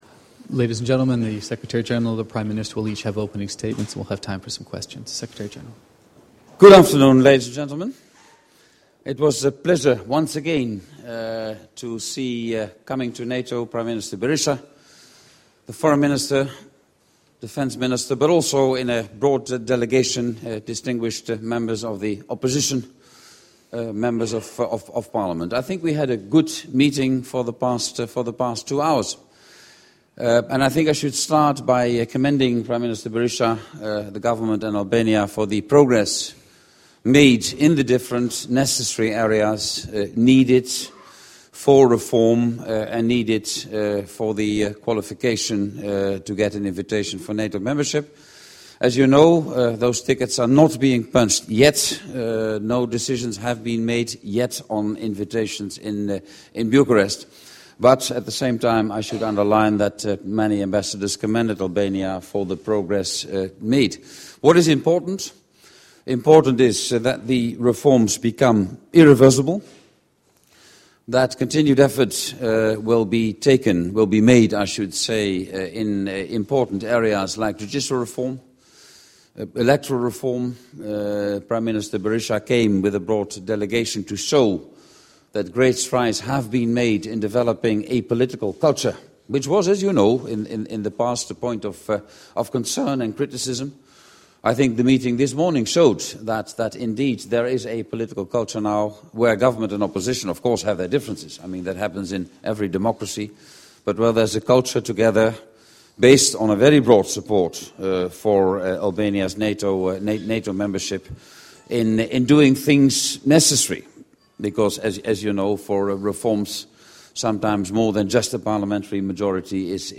Joint press point with NATO Secretary General, Jaap de Hoop Scheffer and the Prime Minister of Albania, Mr. Sali Berisha